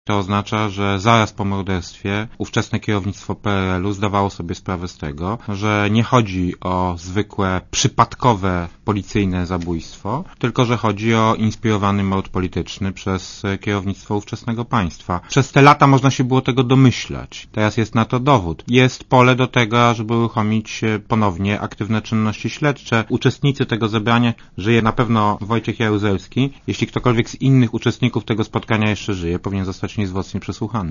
Posłuchaj komentarza Jana Rokity
Skoro ten dowód się pojawił, to jest pole do tego, ażeby uruchomić ponownie aktywne czynności śledcze - powiedział Rokita w Radiu Zet.